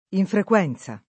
[ infrek U$ n Z a ]